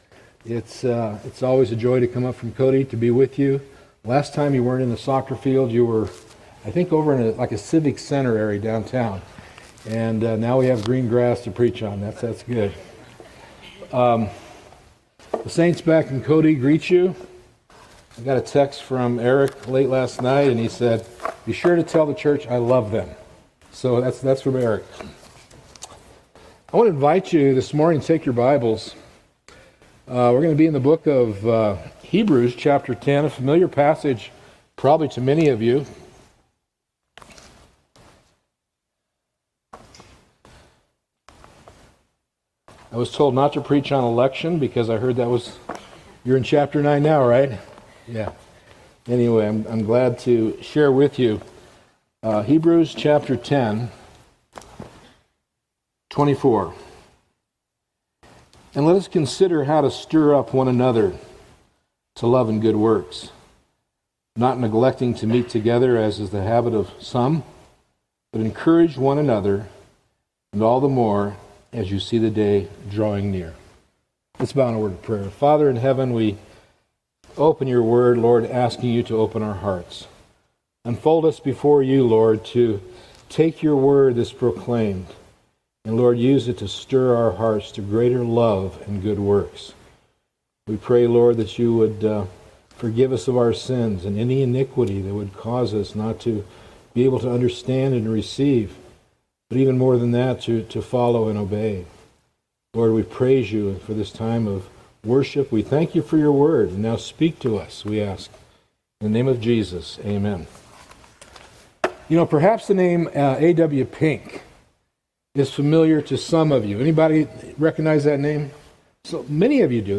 [sermon] Life in the Body Hebrews 10:23-25 | Cornerstone Church - Jackson Hole